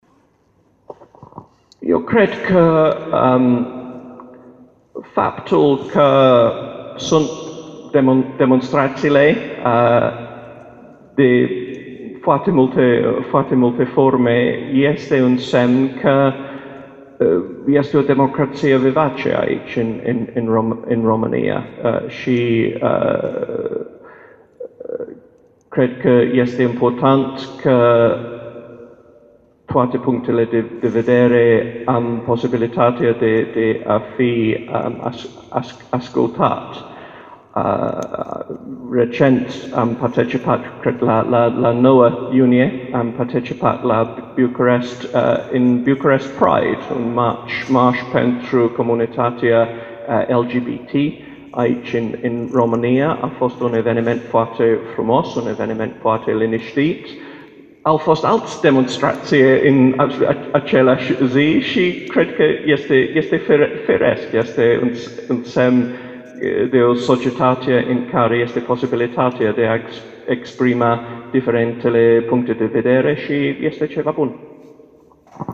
Conferința ambasadorului Marii Britanii, Paul Brummell( foto) pe teme de istorie, diplomație, dar și sociale, s-a desfășurat aștăzi în Sala a Voievozilor a Palatului Culturii.